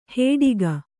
♪ hēḍiga